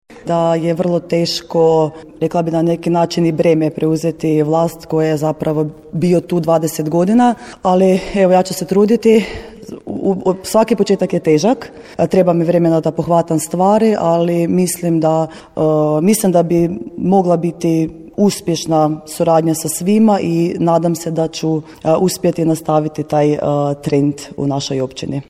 Dan Općine Orehovica 21. svibnja novoizabrana načelnica Dijana Novak, koja je samo dan ranije stupila na dužnost, iskoristila je za kratko predstavljanje i razgovor s predstavnicima civilnog društva i institucija s područja općine.